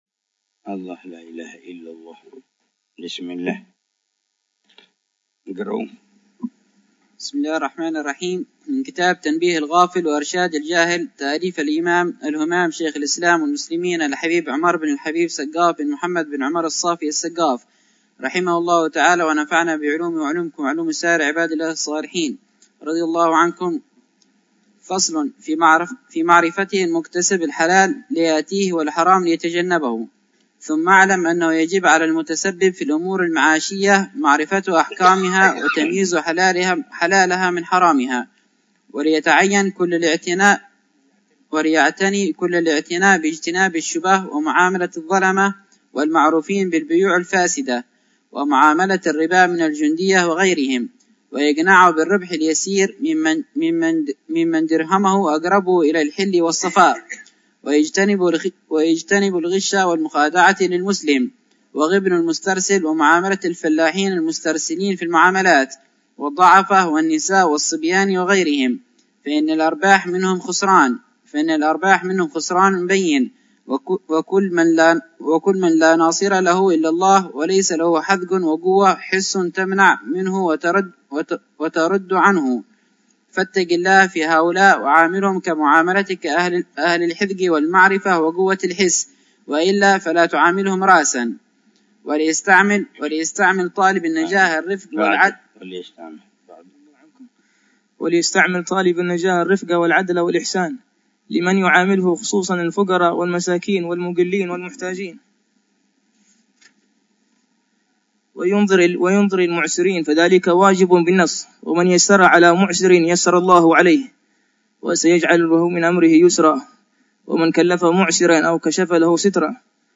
الدرس الثالث عشر من شرح العلامة الحبيب عمر بن محمد بن حفيظ لكتاب : تنبيه الغافل وإرشاد الجاهل للإمام الحبيب : عمر بن سقاف بن محمد الصافي السقا